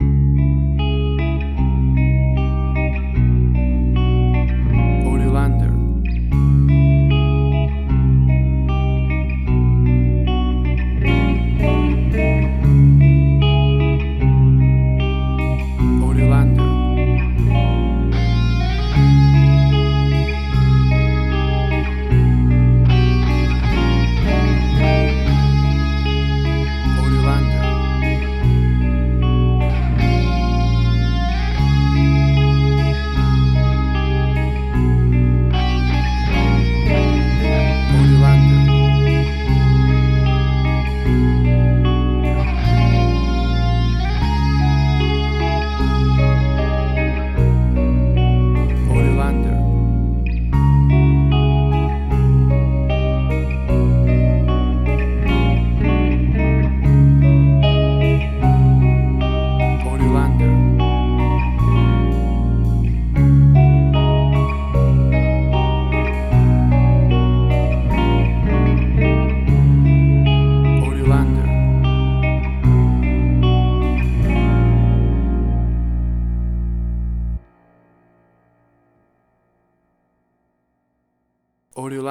Hard Rock, Similar Black Sabbath, AC-DC, Heavy Metal.
Tempo (BPM): 78